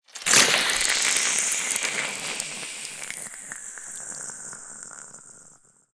Acid_Near_03.ogg